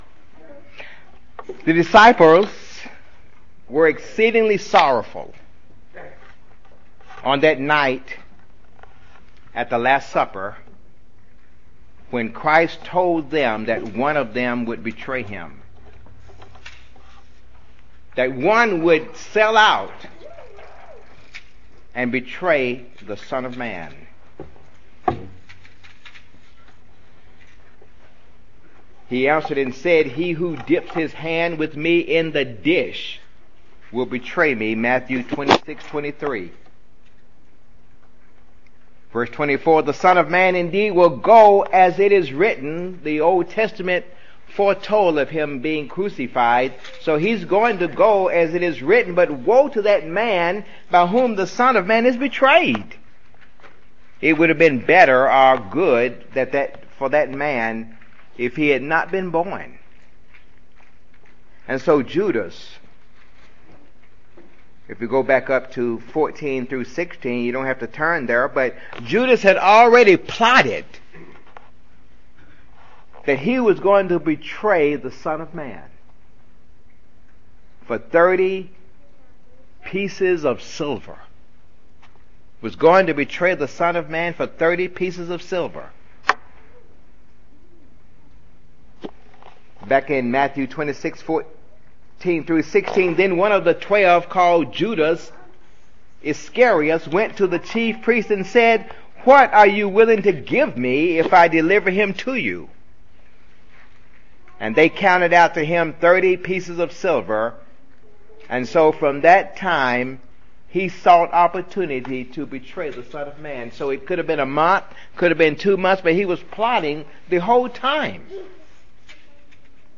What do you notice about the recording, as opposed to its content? Given in Jackson, TN